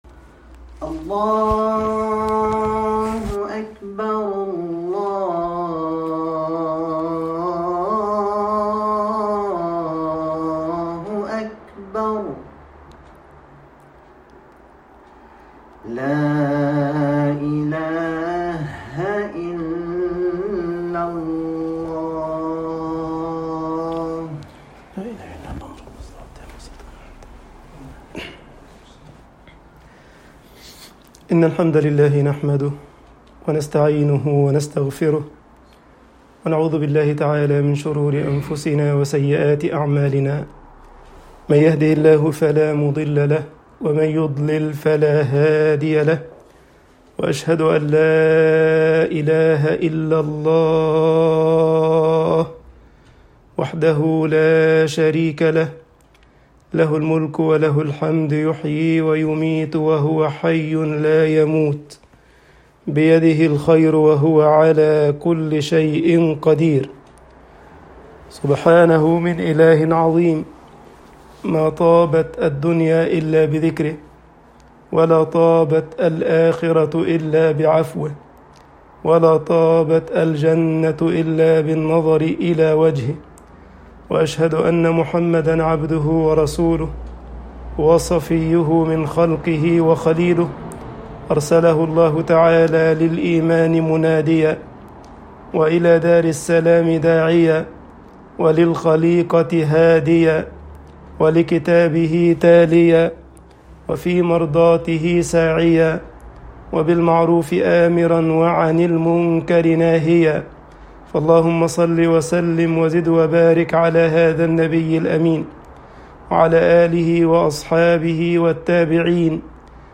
خطب الجمعة والعيد